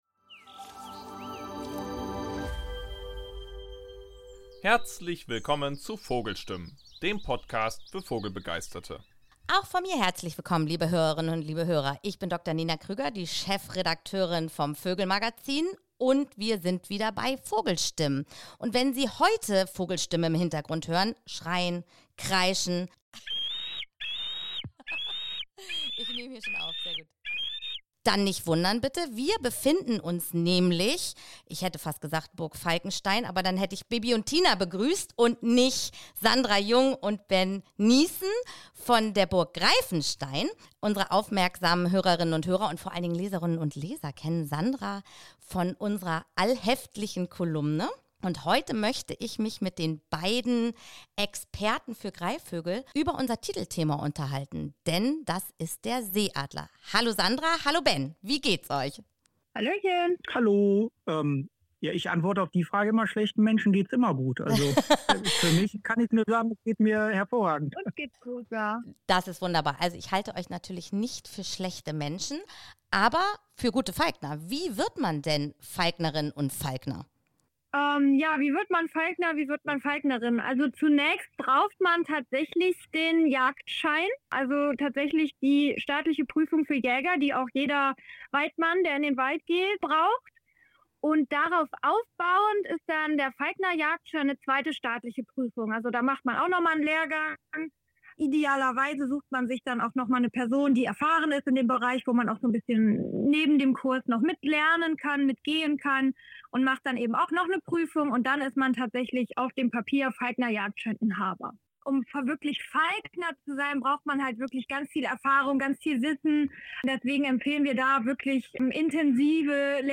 Deshalb haben wir ihm nicht nur den Titel und die Titelgeschichte der Ausgabe Ausgabe 5/2025 des VÖGEL Magazins gewidmet, sondern sprechen auch mit zwei Ex...